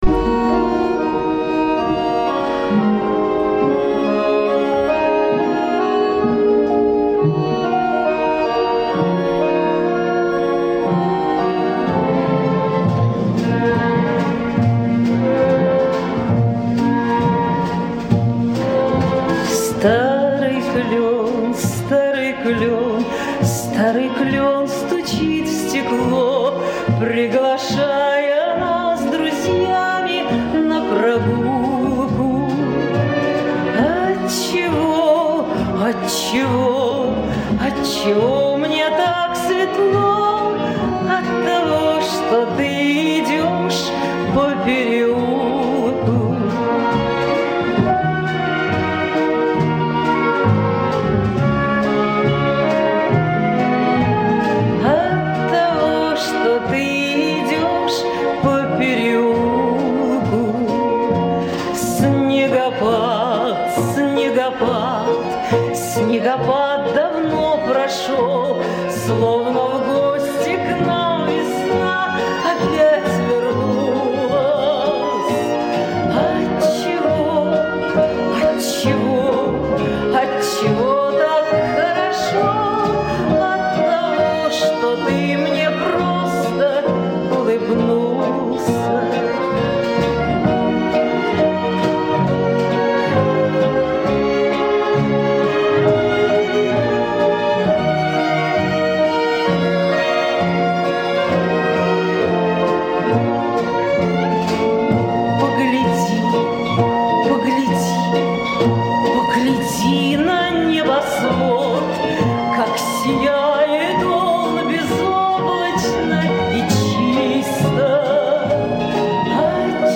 • Жанр: Детские песни
советские детские песни